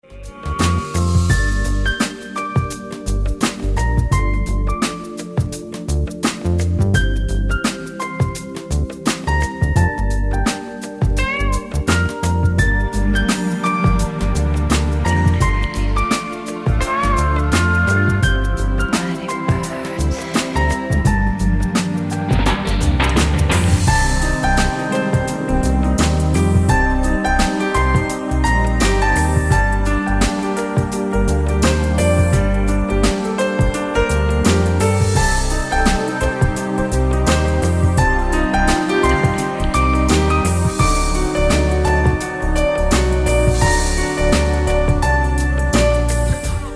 Приятная инструментальная музыка